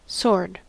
Ääntäminen
US : IPA : /ˈsɔɹd/ US : IPA : /ˈsoʊ.ɚd/ UK : IPA : /ˈsɔːd/